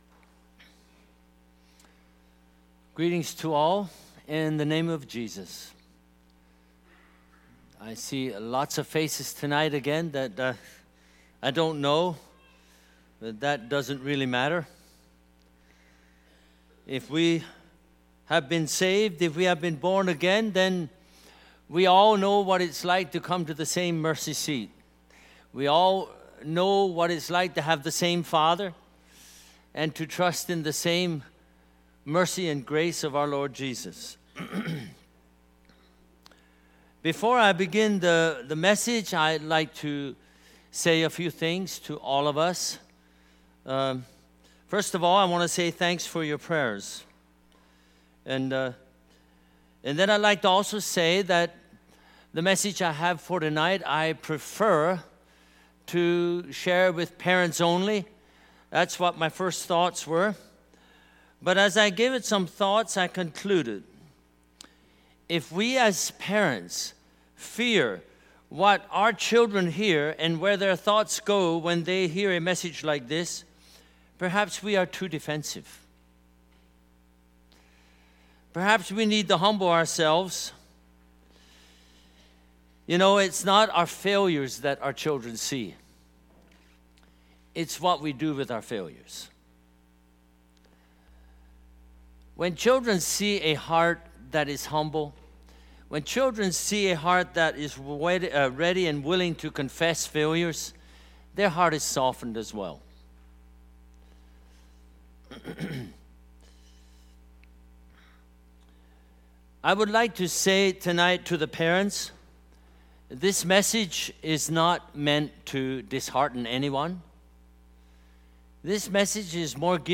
Bible Teaching Service Type: Friday Evening